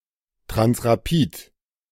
Transrapid (German: [tʁansʁaˈpiːt]
De-Transrapid.ogg.mp3